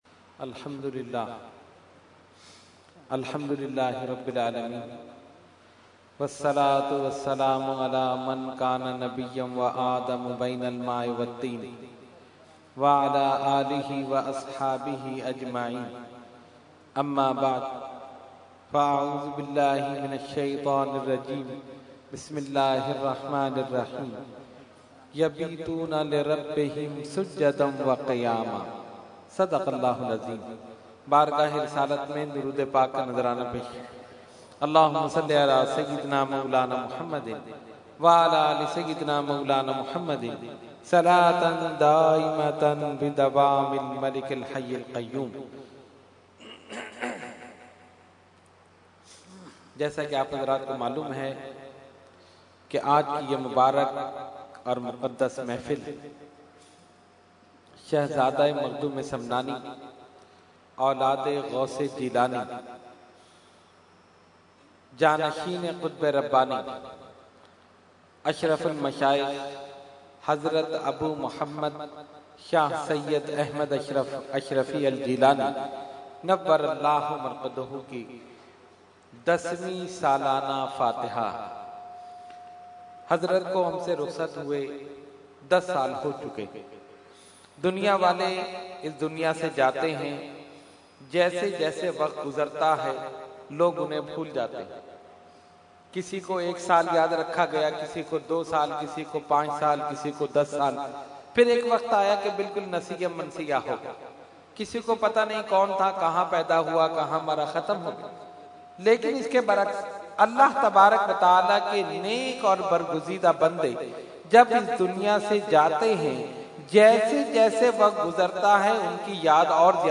Category : Speech | Language : UrduEvent : Urs Ashraful Mashaikh 2015